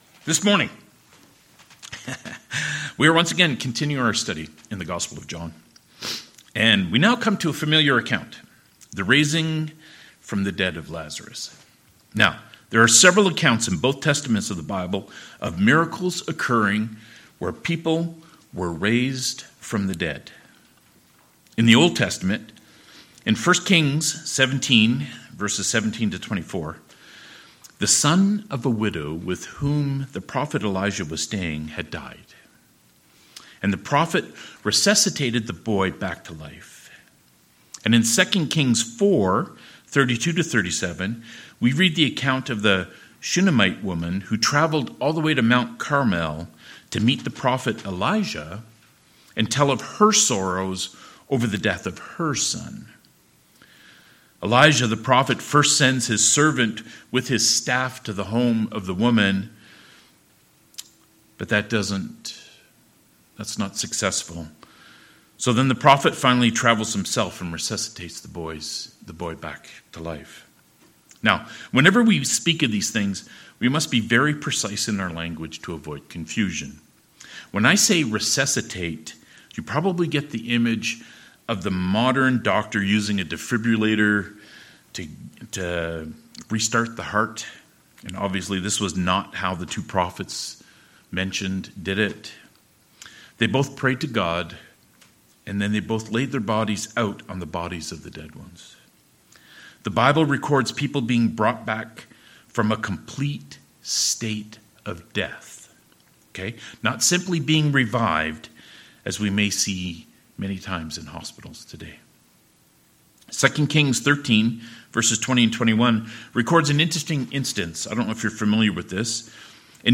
Passage: John 11: 38-44 Service Type: Sermons